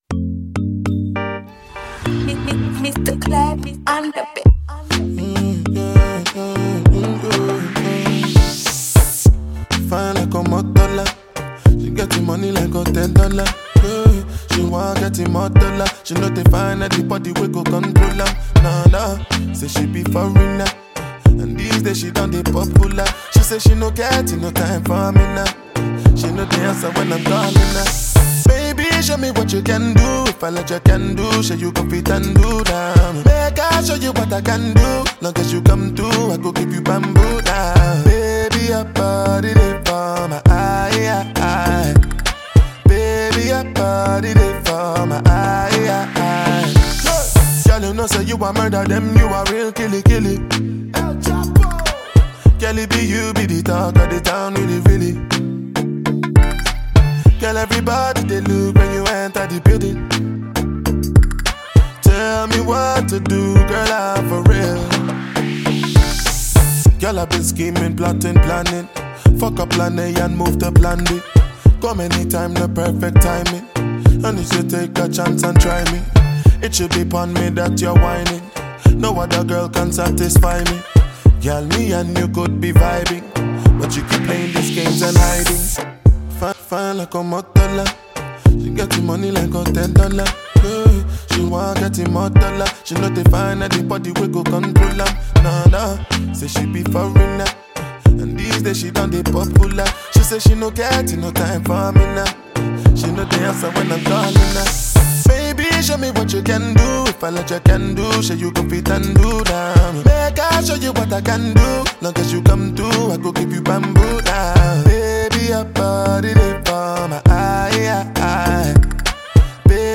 Released in 2019, it mixes Afrobeat, reggae, and hip-hop.